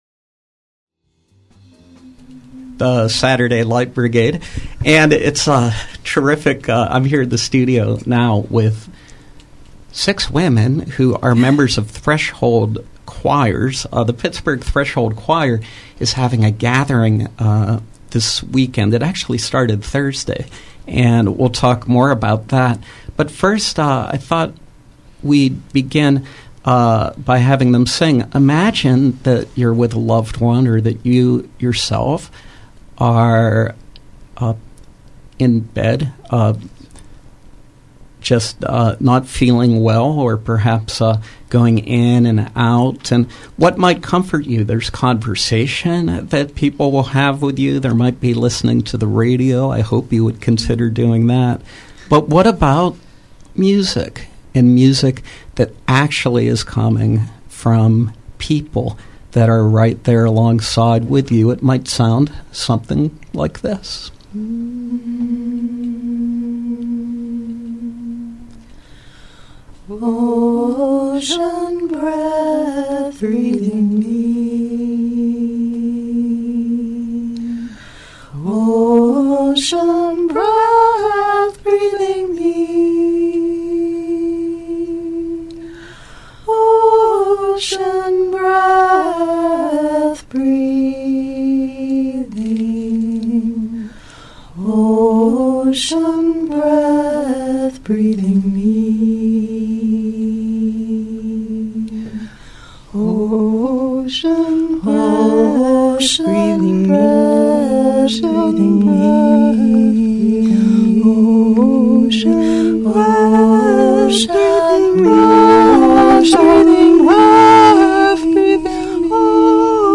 Live Music: Pittsburgh Threshold Choir
From 3/22/14: Members of the Pittsburgh Threshold Choir and bedside singers from other parts of the U.S. on the Appalachian Regional Gathering of bedside singers and the history of bedside singing